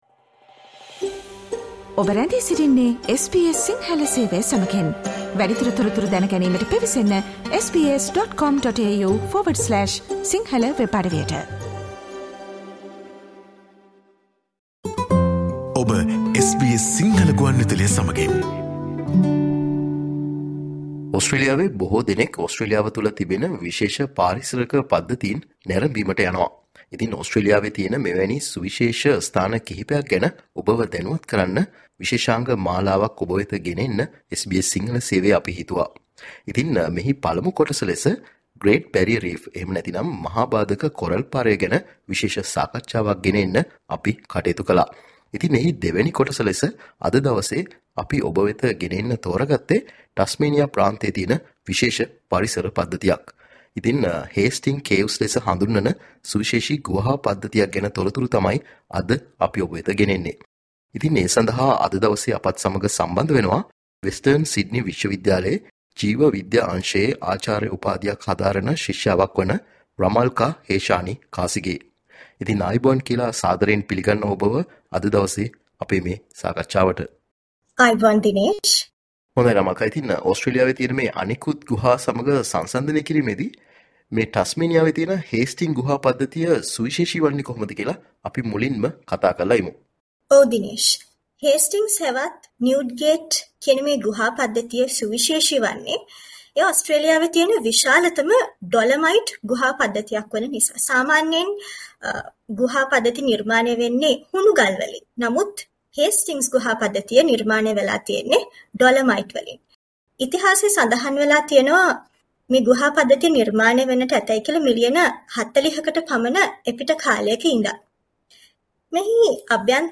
SBS Sinhala Interview about Hastings caves in Tasmania